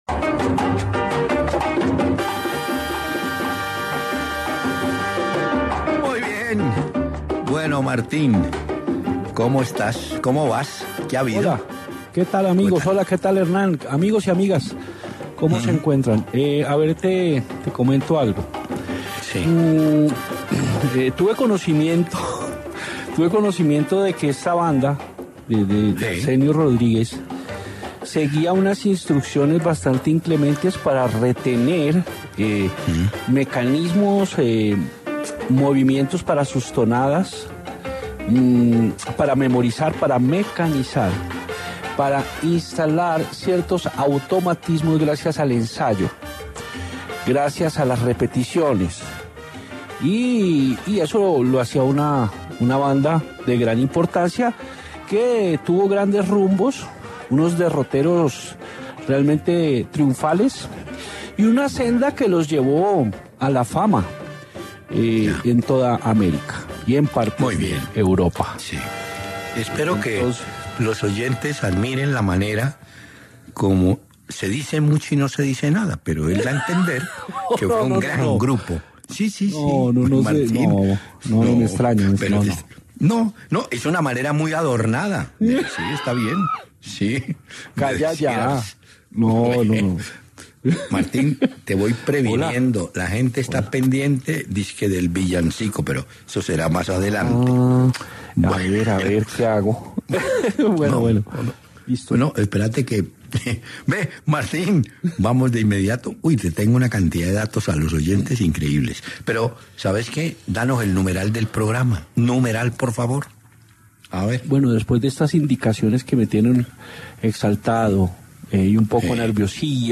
Hernán Peláez y Martín de Francisco conversaron sobre cuáles serán los equipo finalistas de la liga colombiana.